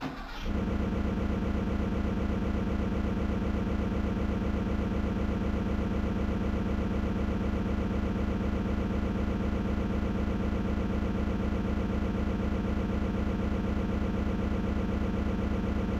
Эффект работающего двигателя (Start Engine Sound FX)
Тут вы можете прослушать онлайн и скачать бесплатно аудио запись из категории «Приборы и техника».